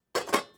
SFX_Cooking_Pot_01.wav